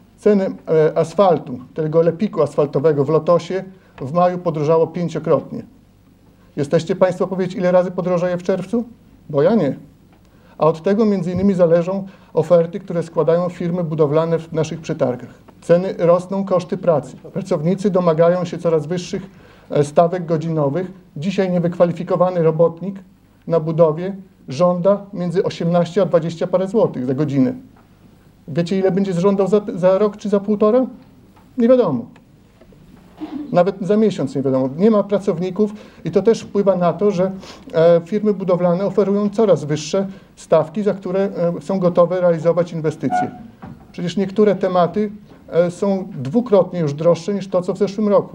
W odpowiedzi Zbigniew De-Mezer z Klubu „Łączą nas Suwałki” pytał, gdzie byli radni PiS w trakcie całego roku, kiedy to zmieniano budżet.
Do zarzutów opozycji odnosił się Łukasz Kurzyna, zastępca prezydenta miasta. Wyjaśniał między innymi, że obecnie wykonawcy dyktują warunki i nie sposób oszacować, ile pieniędzy pochłonie dana inwestycja.